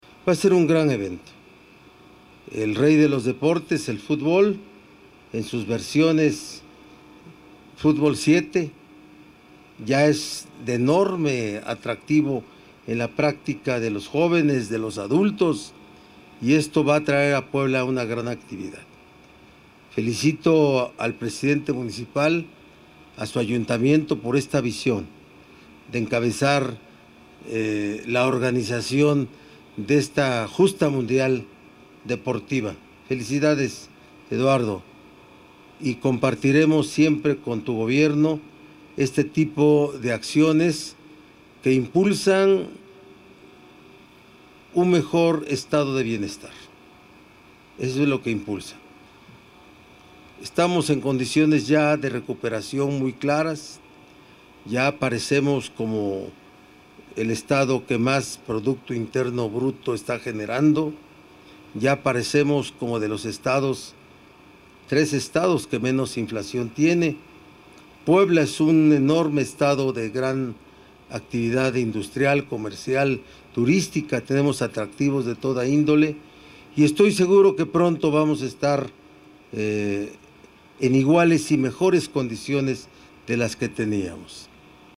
Al presidir la Firma del Convenio para Presentar a Puebla Capital como Sede Oficial del Mundial de la Federación Internacional de Futbol 7 en el 2023, el gobernador Miguel Barbosa Huerta destacó que su administración coadyuva con los 217 ayuntamientos para impulsar un mejor estado de bienestar y acciones que fortalezcan la reactivación económica.